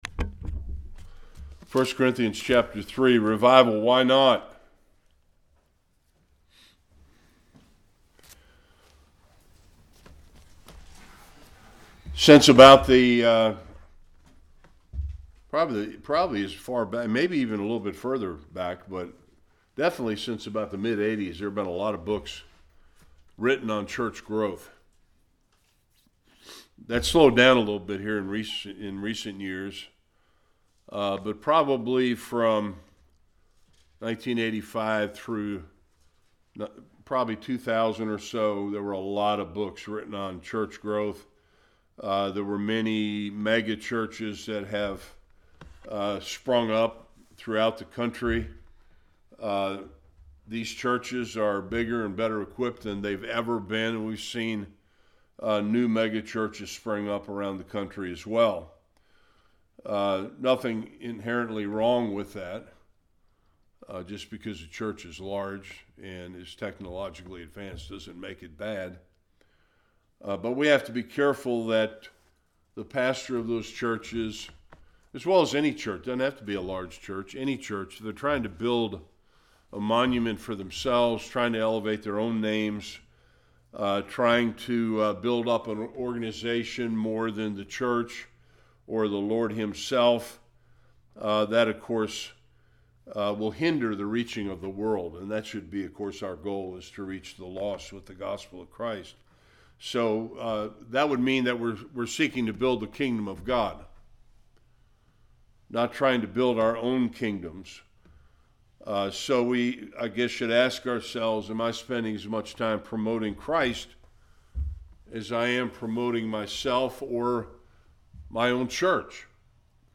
1 Corinthians 3 Service Type: Bible Study Examining proper methods and motives in building Christ’s Church.